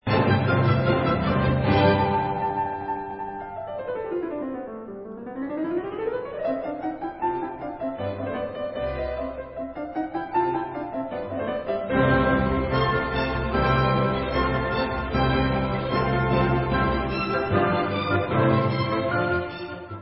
sledovat novinky v oddělení Klavírní koncerty
Klasika